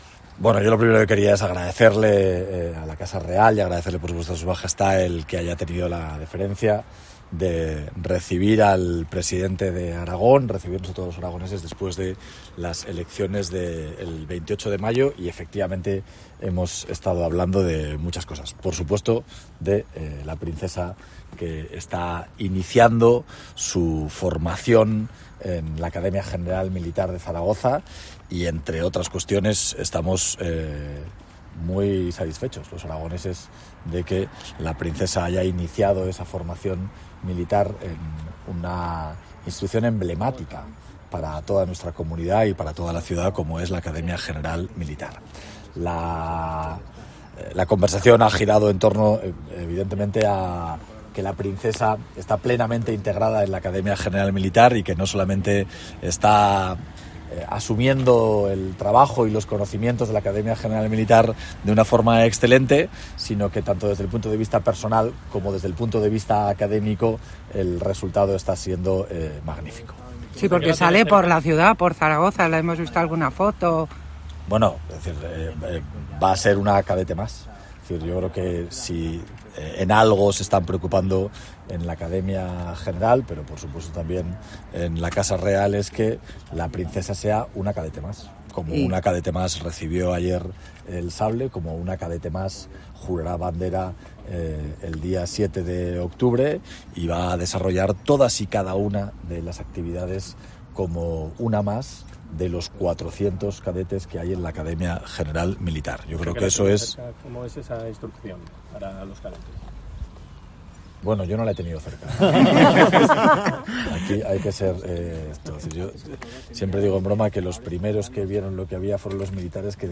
Declaraciones del presidente Jorge Azcón, tras la reunión con el Rey Felipe VI en Zarzuela.